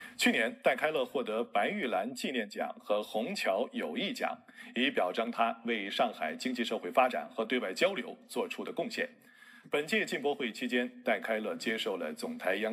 権威あるプロフェッショナルトレーニングボイス
プロフェッショナルトレーニング、コンプライアンスモジュール、エグゼクティブ開発のために設計された、明瞭で自然な響きのAIボイスでインパクトのある企業教育を提供します。
当社のAIは、複雑なトレーニングコンセプトを絶対的な精度で伝える洗練された人間らしいトーンを提供します。
このボイスは、熟練した企業教育者のように聞こえるように特別に設計されており、学習の旅を通じて信頼と信頼性の感覚を提供します。